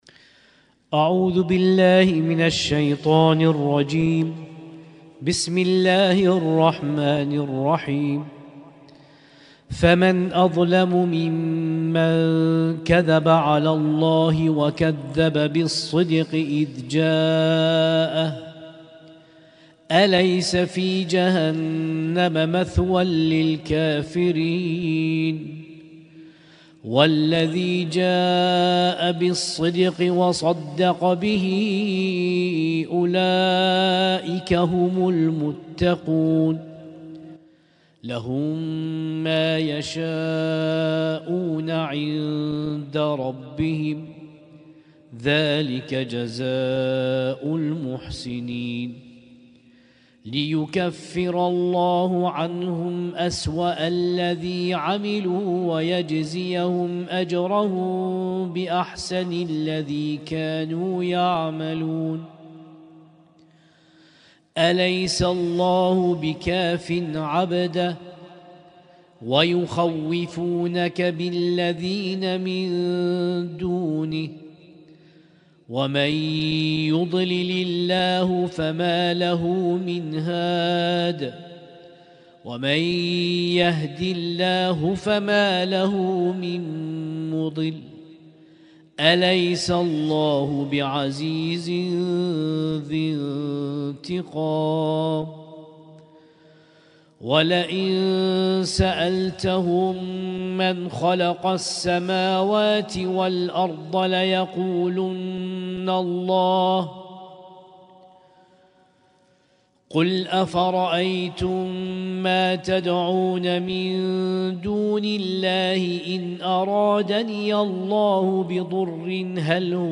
اسم التصنيف: المـكتبة الصــوتيه >> القرآن الكريم >> القرآن الكريم - شهر رمضان 1446